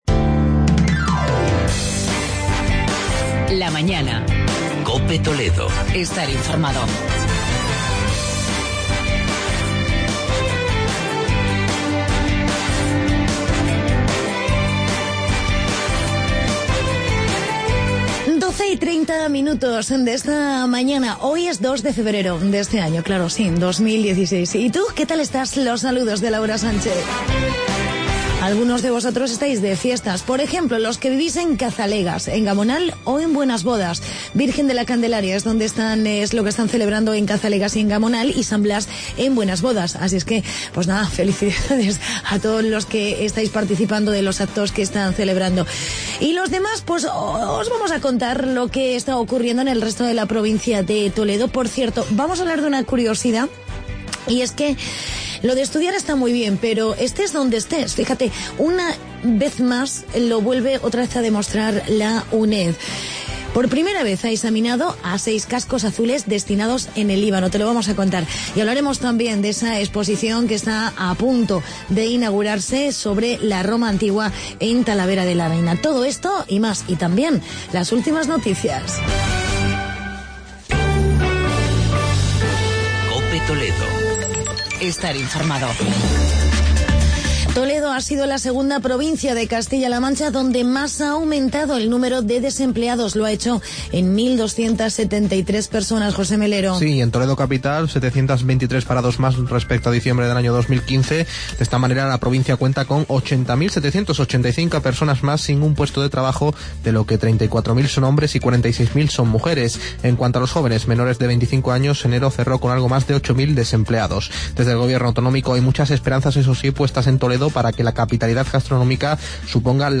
COPE TALAVERA